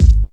DEEP.wav